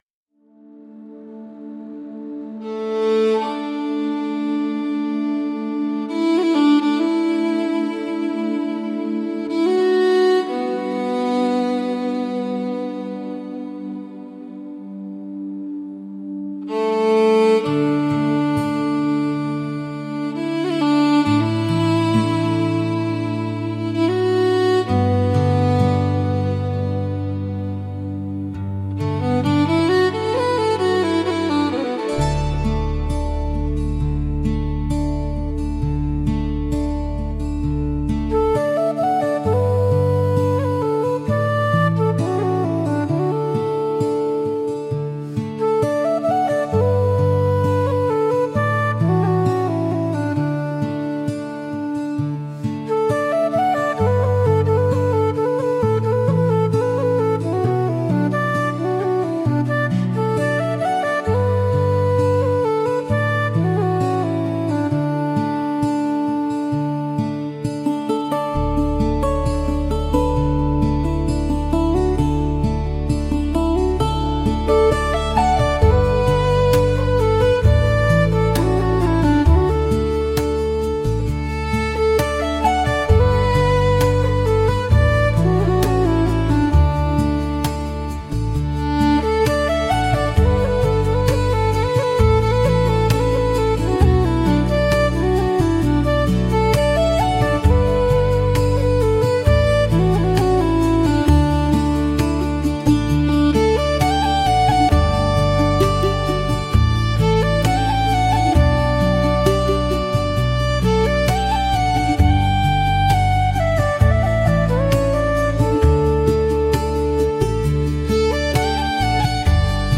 calm instrumental playlist